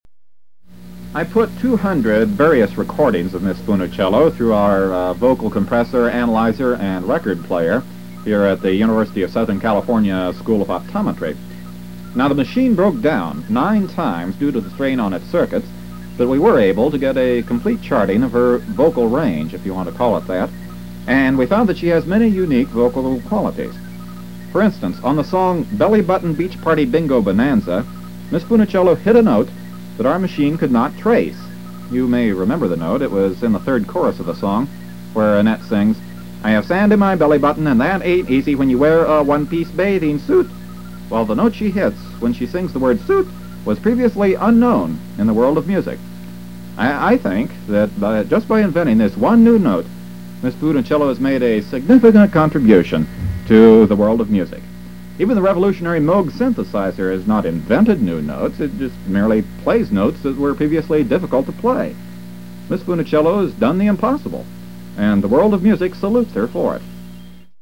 In the other bit, I claimed that in a certain song, Annette had hit a previously unknown note when she sang the word "suit."  I raised my pitch when I said "suit," but my voice cracked at the same time, and the result truly was an unrecognizable pitch.
Recorded July 16, 1970, at WAER-FM.
Notice that when I spoke the word "suit" again three seconds later, I failed to repeat the effect.